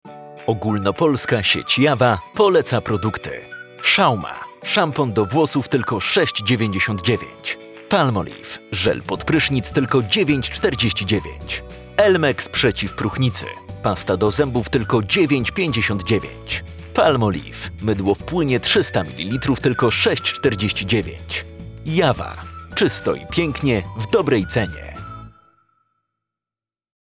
Male 20-30 lat
Spot reklamowy